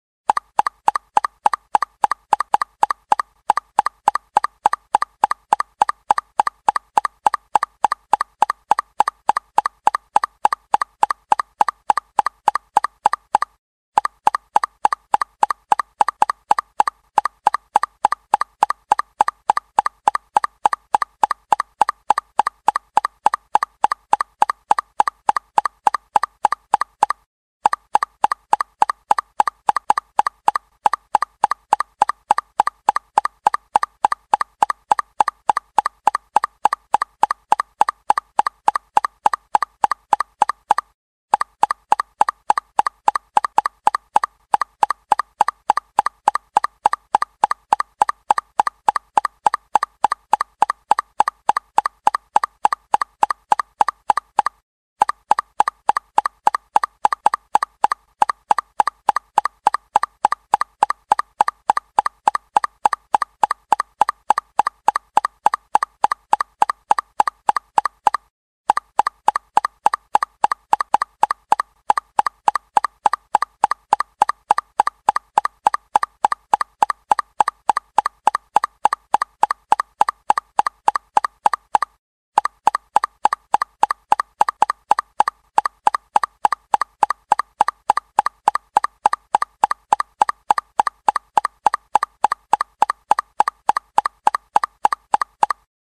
VKメッセージ音 無料ダウンロードとオンライン視聴はvoicebot.suで